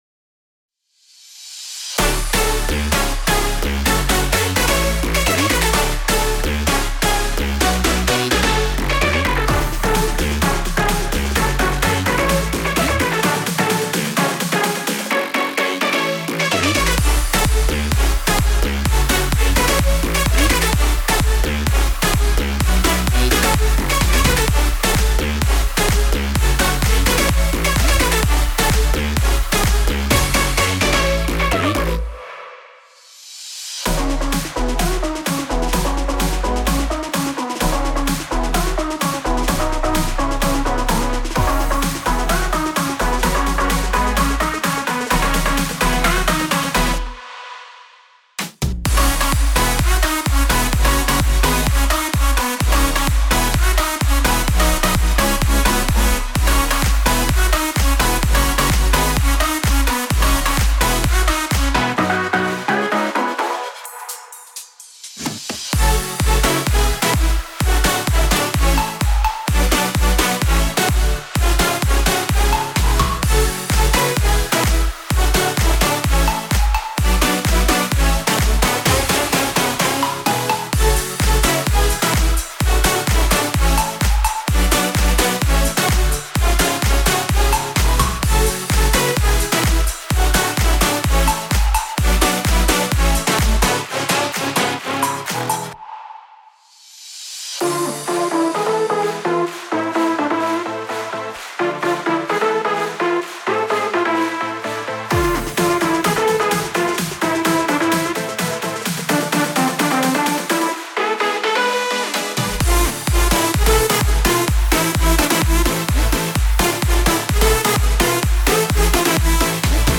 • Multi-Genre